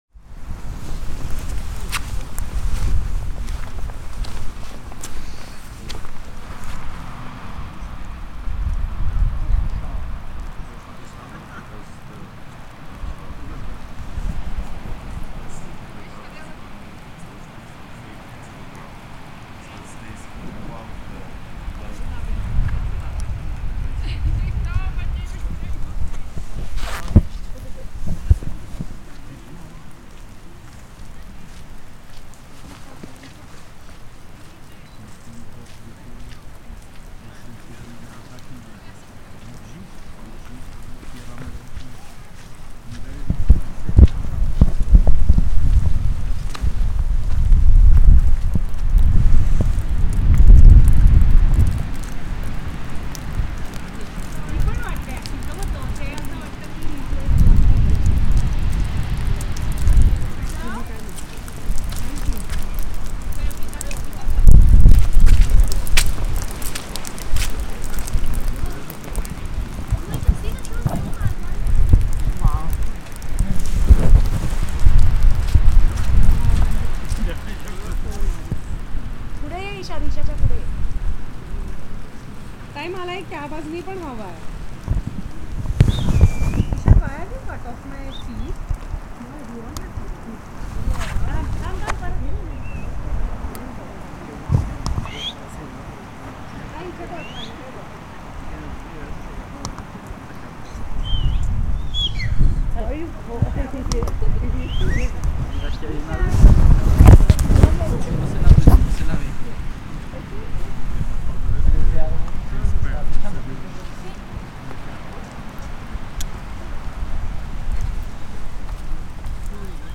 This recording was captured in Fanal, a vast forested area with an enchanting atmosphere and ancient-looking trees that are part of the indigenous Laurisilva forest. In the recording, you can hear me walking, sounds of people talking, taking photos and wandering through the mist. Between some powerful winds, theres some calmer moments where you can hear the soft patter of raindrops falling from the trees. Given the strong winds and my limited equipment—a small RøDE VideoMic Me-L for iPhone with a basic windscreen—it was challenging to avoid wind noise entirely. To present the best audio experience, I carefully edited and compiled the highest-quality segments in chronological order.
——————— This sound is part of the Sonic Heritage project, exploring the sounds of the world’s most famous sights.